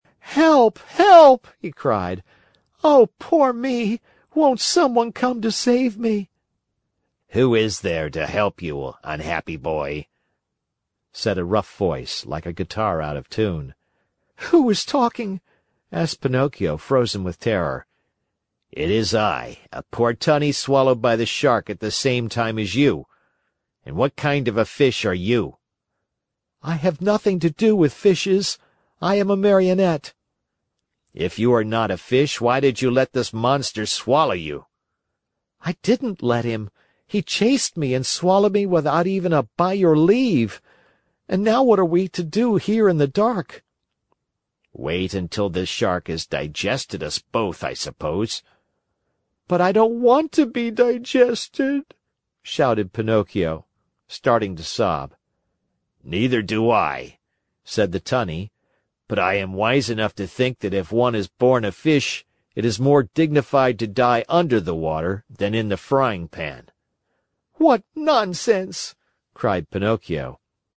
在线英语听力室木偶奇遇记 第146期:匹诺曹落入鱼腹(8)的听力文件下载,《木偶奇遇记》是双语童话故事的有声读物，包含中英字幕以及英语听力MP3,是听故事学英语的极好素材。